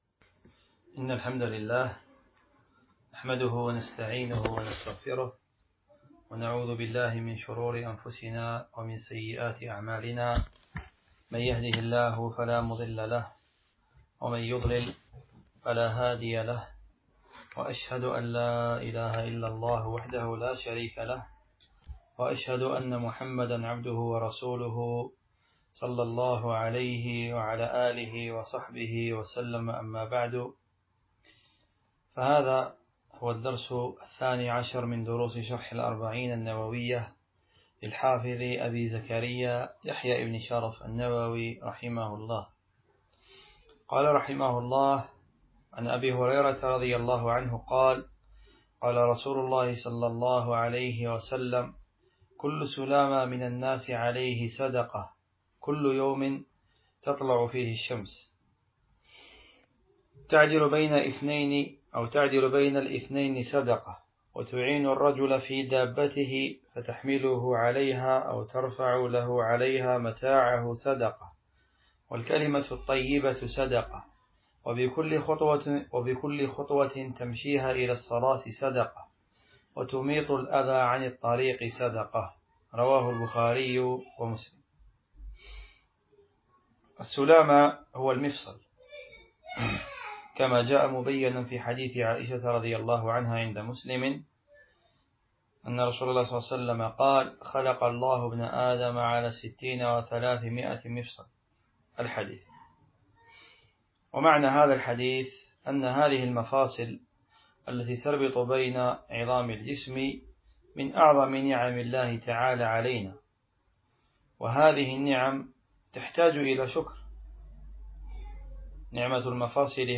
شرح الأربعون النووية الدرس 12